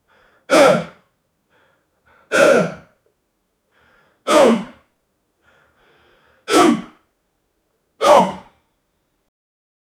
man quickly lifting weight, grunt from energy exertion
man-quickly-lifting-weigh-5fh4shkb.wav